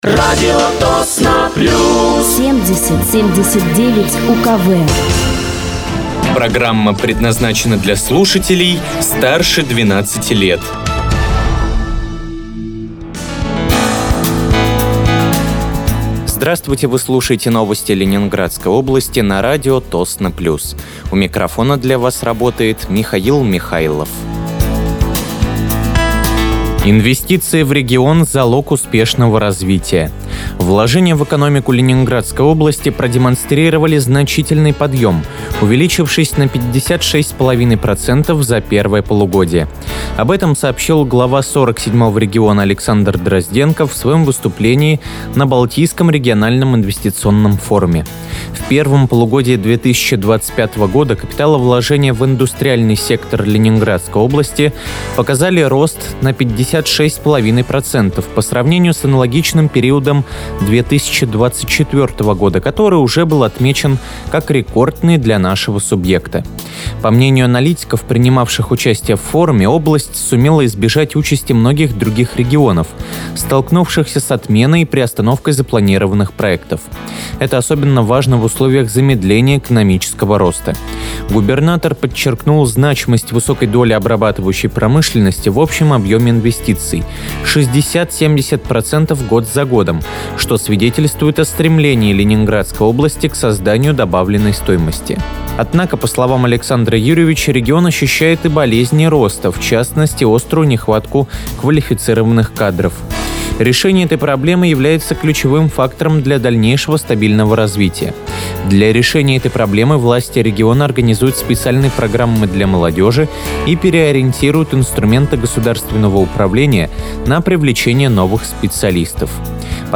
Выпуск новостей Ленинградской области от 22.09.2025
Вы слушаете новости Ленинградской области от 22.09.2025 на радиоканале «Радио Тосно плюс».